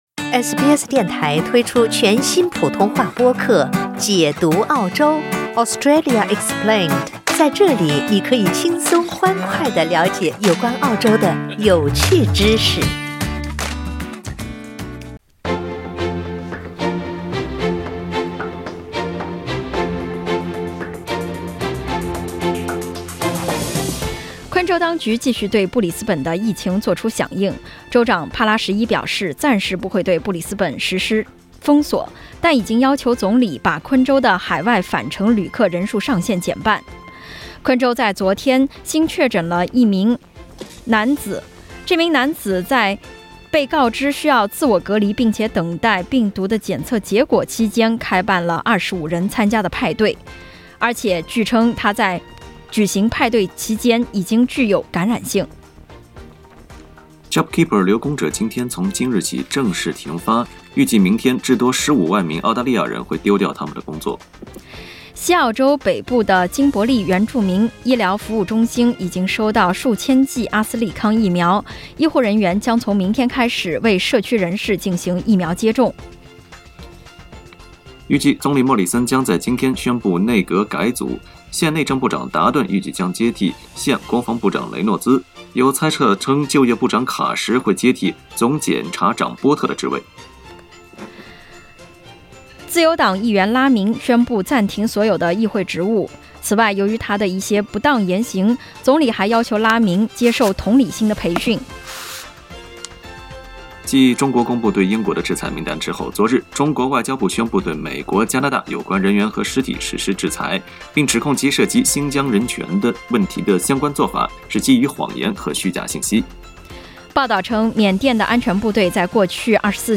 SBS早新闻（3月28日）